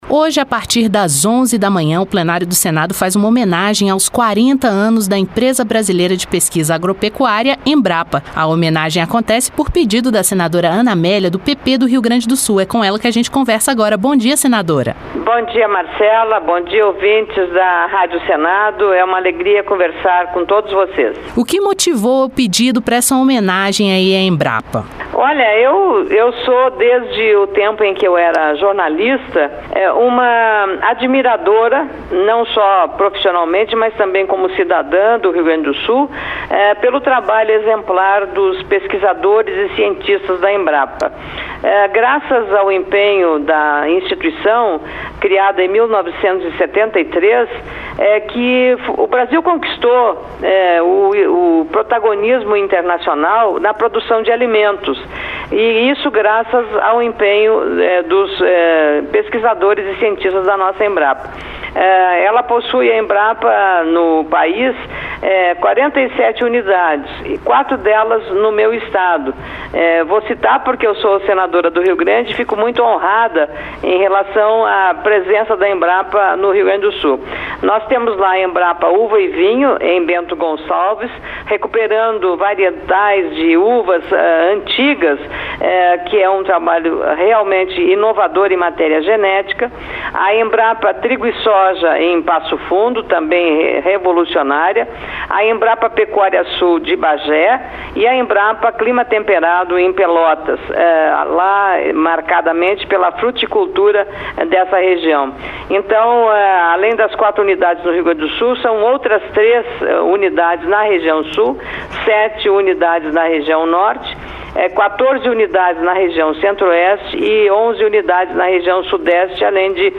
Entrevistas regionais, notícias e informações sobre o Senado Federal